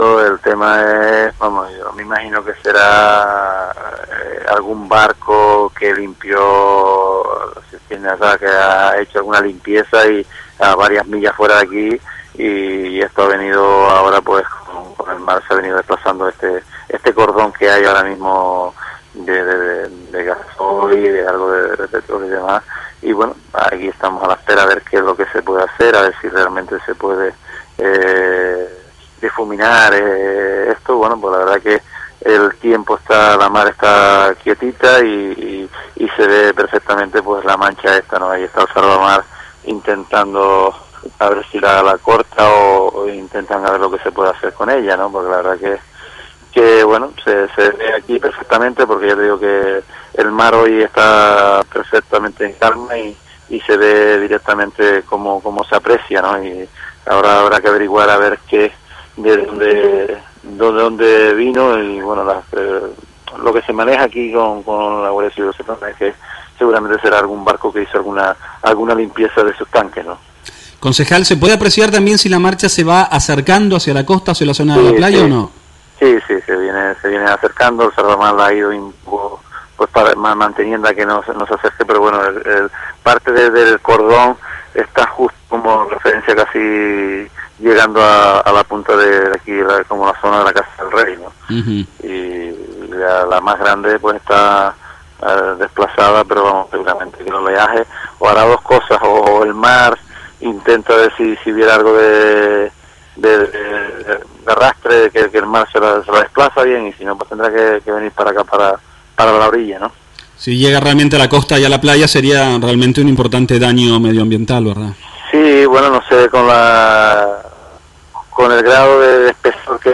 Las autoridades municipales no ocultaban su preocupación poco antes de las 10:00 de la mañana, cuando la mancha se estaba acercando cada vez más a la costa, y se temía que pudiera llegar a tierra, afectando incluso playa Bastián, según había señalado a Lancelot Radio Onda Cero el concejal de Medioambiente de Teguise, Domingo Cejas